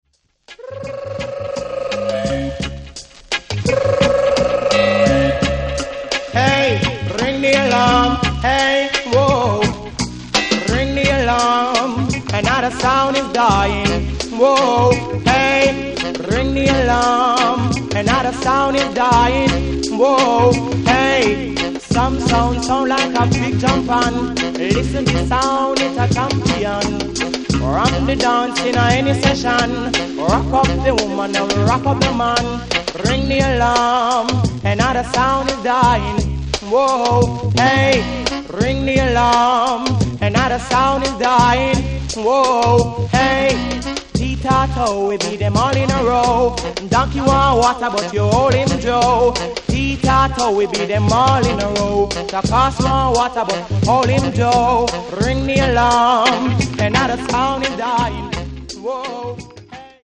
The greatest dancehall riddim of all time!!
Dub. Roots. Reggae. Calypso. Ska. 2 Tone.